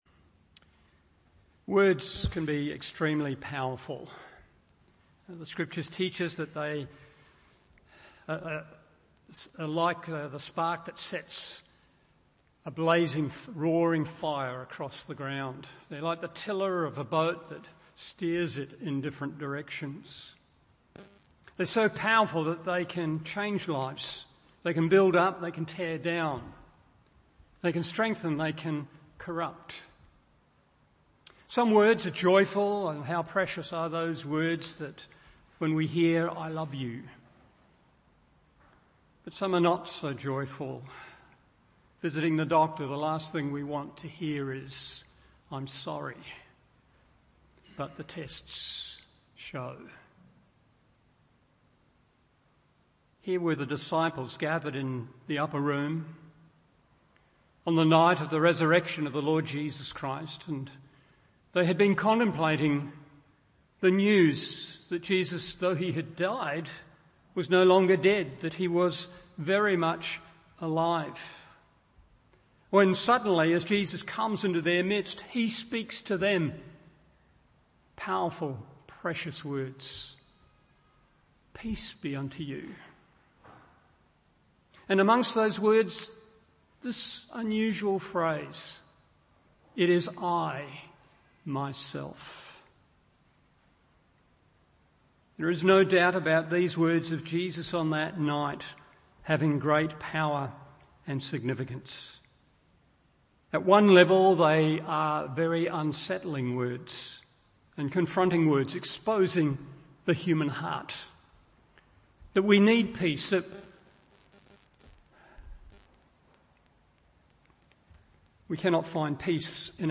Morning Service Luke 24:33-48 1. It’s Too Good to be True 2. It’s Truth is Wonderfully Good…